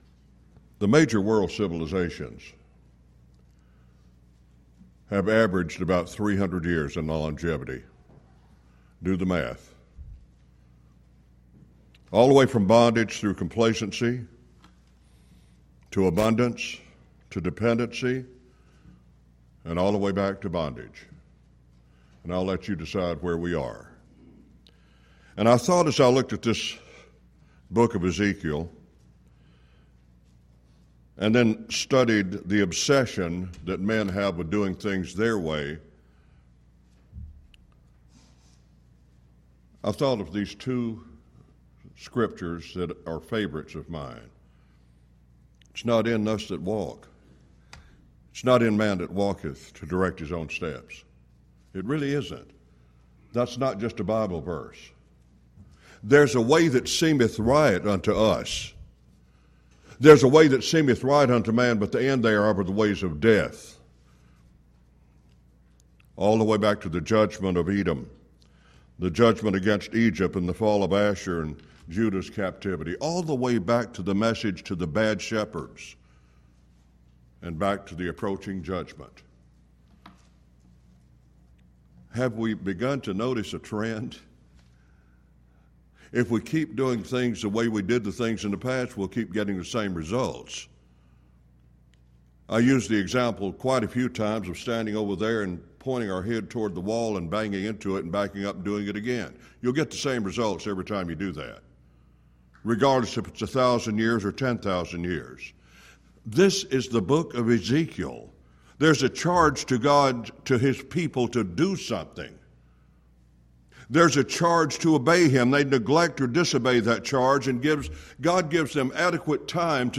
Event: 10th Annual Schertz Lectures Theme/Title: Studies in Ezekiel